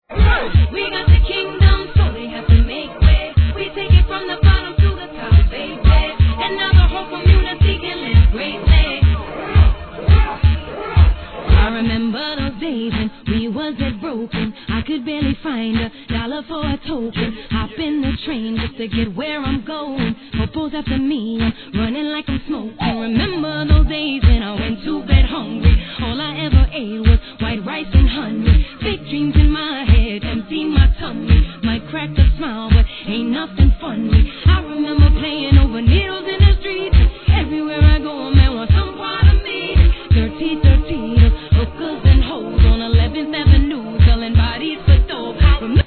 HIP HOP/R&B
サビも彼女が歌っておりますがGOO~D!!